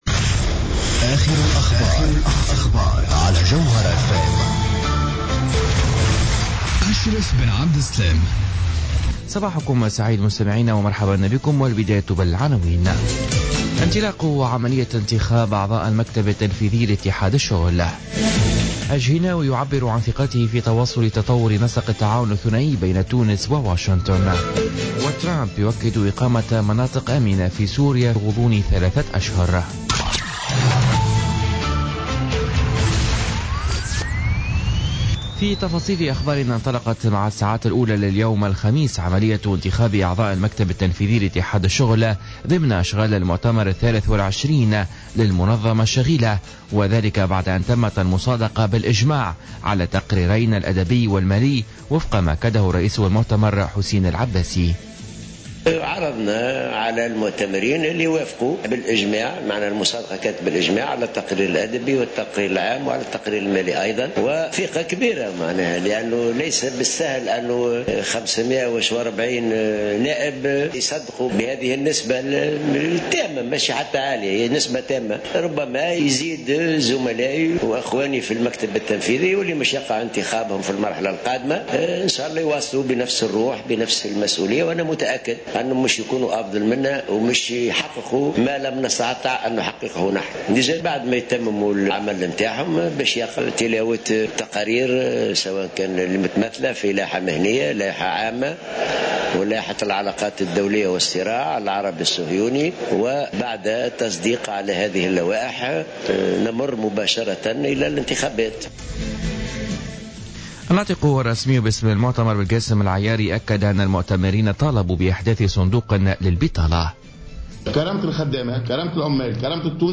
نشرة أخبار السابعة صباحا ليوم الخميس 26 جانفي 2017